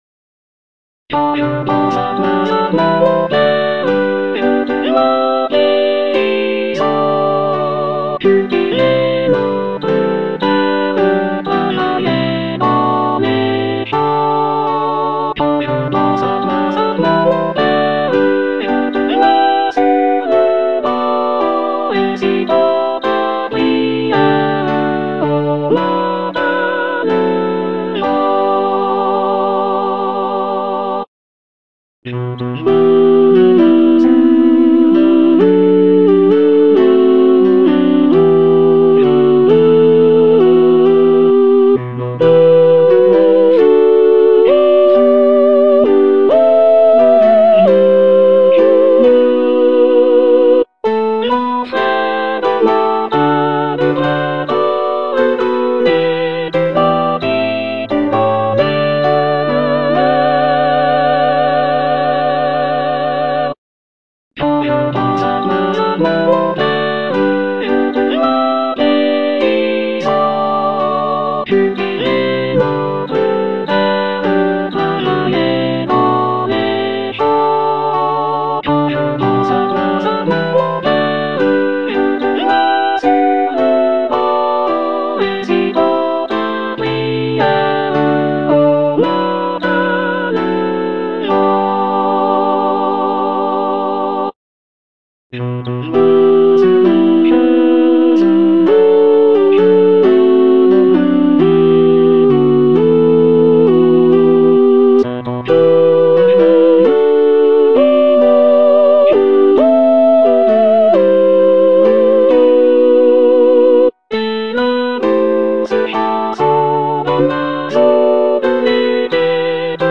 Soprano (Emphasised voice and other voices)